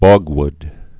(bôgwd, bŏg-)